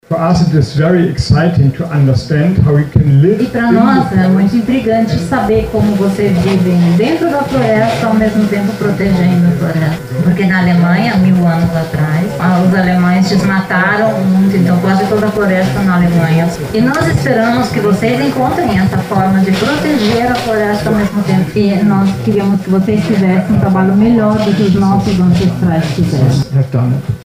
O vice-chanceler e ministro federal da Economia e Ação Climática da Alemanha, Robert Habeck, durante discurso aos moradores da comunidade, em sua maioria indígenas Kambeba, destacou a importância dos amazônidas na proteção da região.
OK-Sonora-Robert-Habeck-vice-chanceler-da-Alemanha.mp3